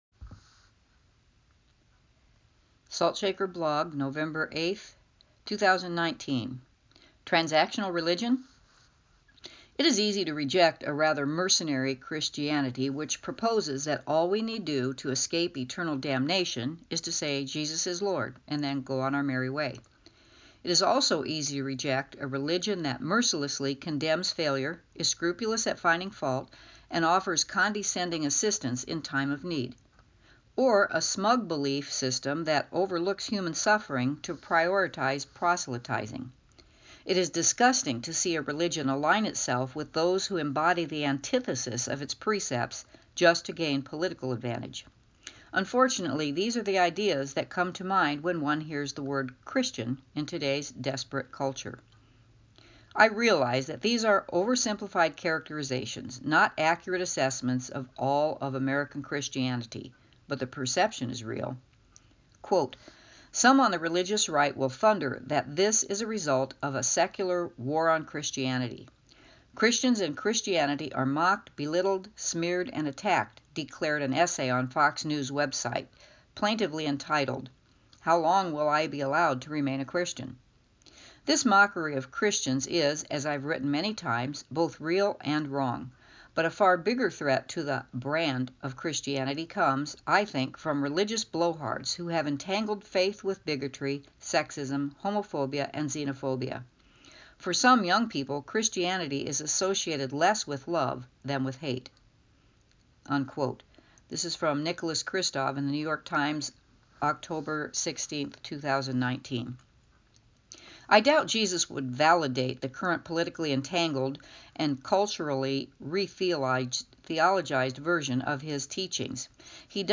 Click for audio version read by author: